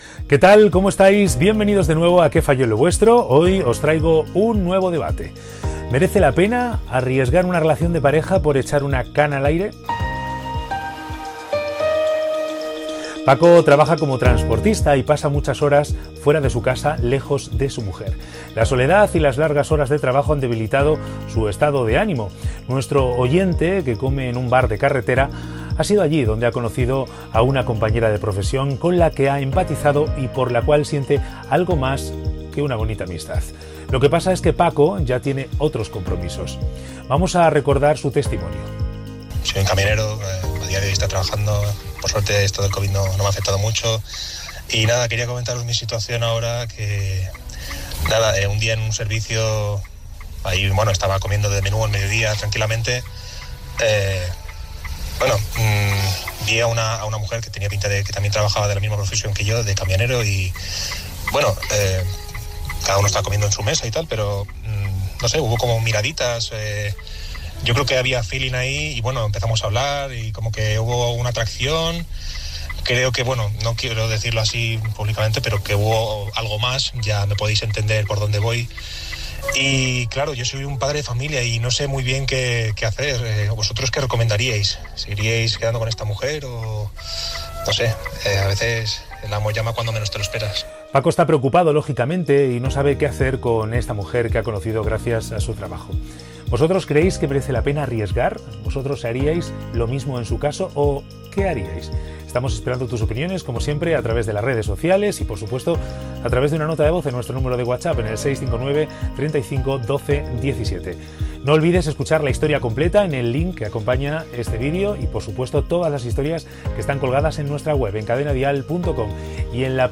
Proposta del tema que es debatrà al programa amb el testimoni d'un camioner i les formes de participar al programa